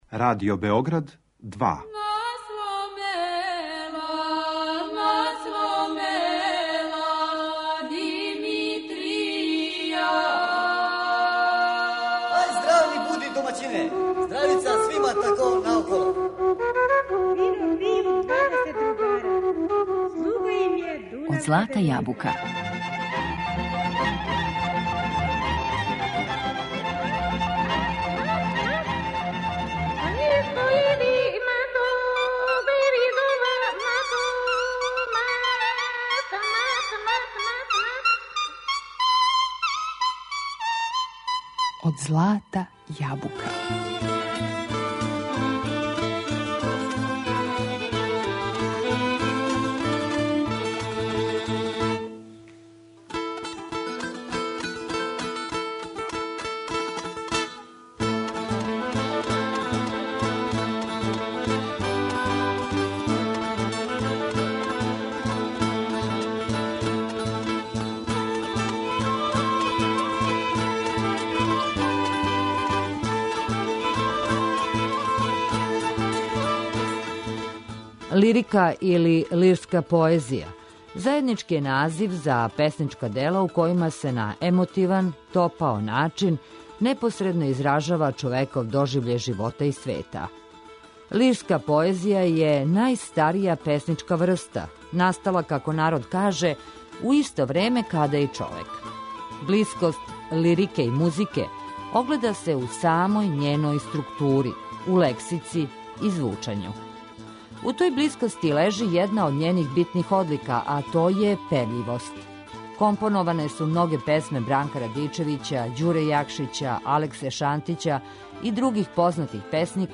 Данас говоримо о љубавној лирици, али са посебним освртом на мотив момка и девојке у народној поезији, а све приче у емисији Од злата јабука, као и увек, осликаћемо народним песмама и играма.